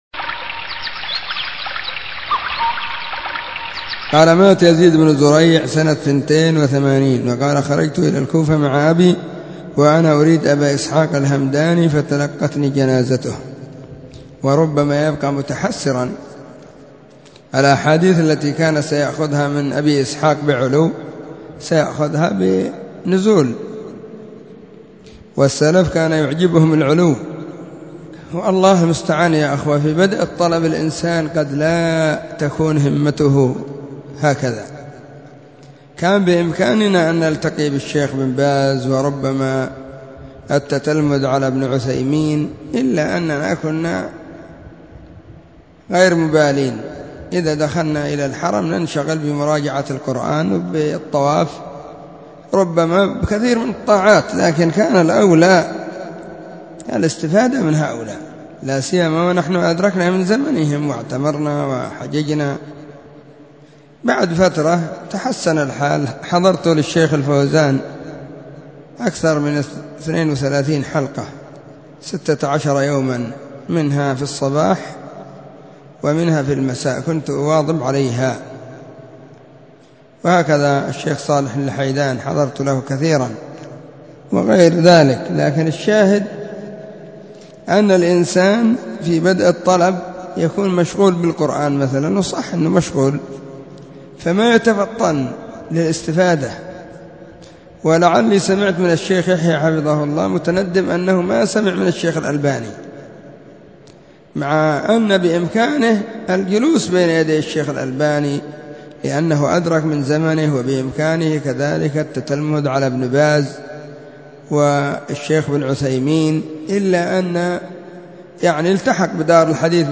نصيحة قيمة بعنوان: المسارعة أيها الكرماء للتتلمذ على يد العلماء